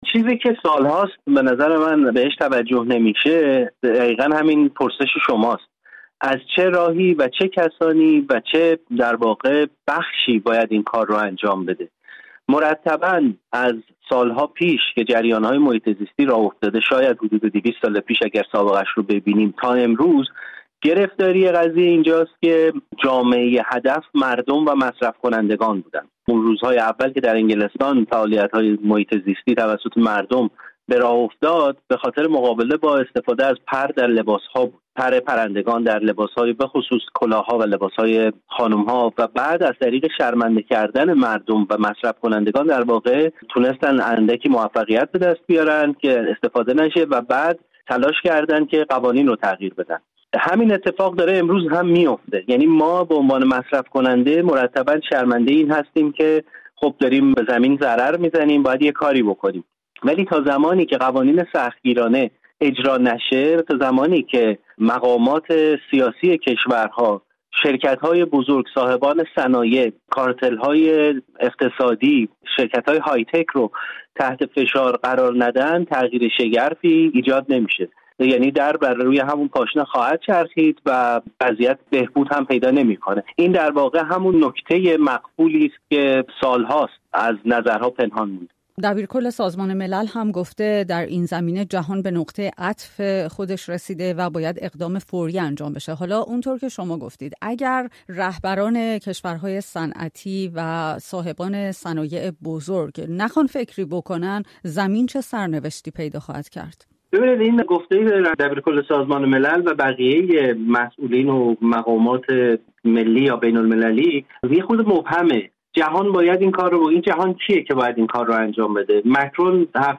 گفت‌وگو